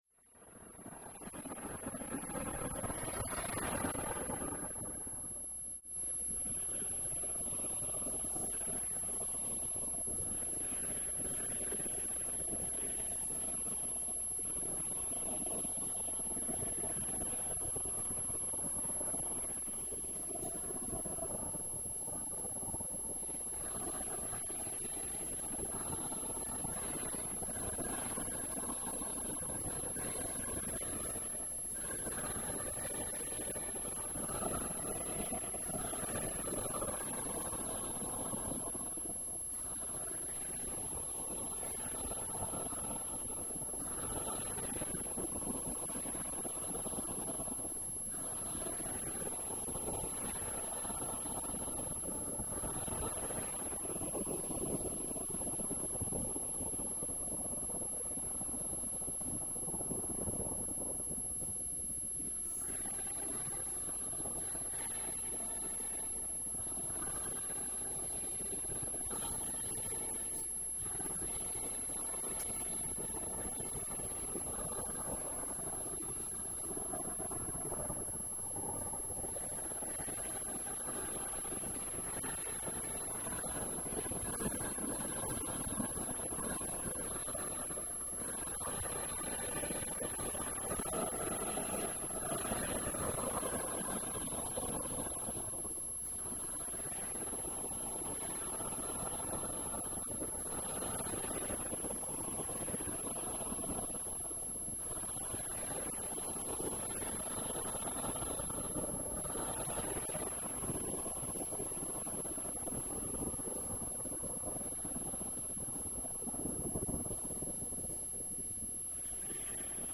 – Version avec choeur mixte, unisson ou 2-4 voix
Brass Band
Easy Listening / Unterhaltung / Variété
Voice & Brass Band
Meditation / Hymne Choral